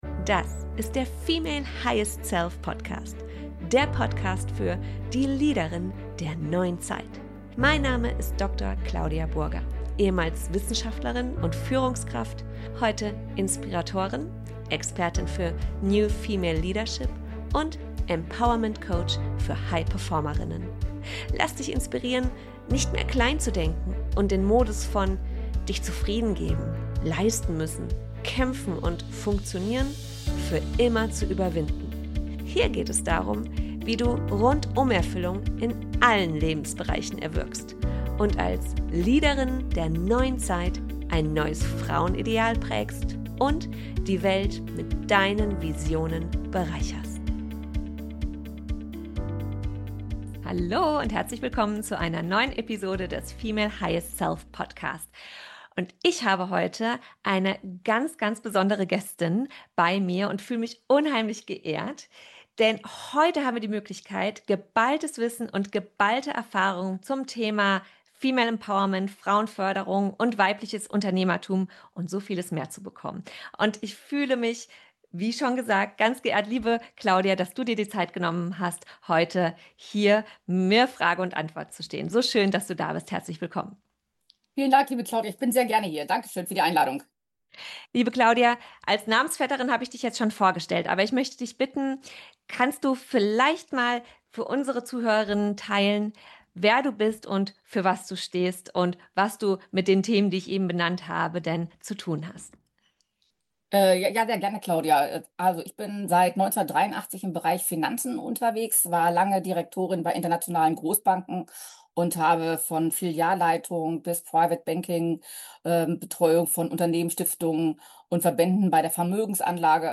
In diesem inspirierenden Interview sprechen wir über ihren Weg in die Führungsetagen, über ihre Haltung, Herausforderungen und ihren unerschütterlichen Glauben an sich selbst – auch in Zeiten, in denen Frauen in Top-Positionen noch absolute Ausnahmen waren.